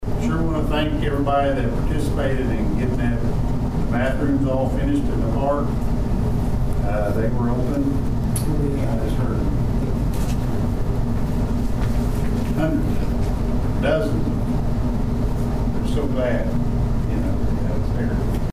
The Dewey City Council and Public Works Authority met for the first time in May on Monday night at Dewey City Hall.
Mayor Tom Hays thanked the people who worked on a recent park project.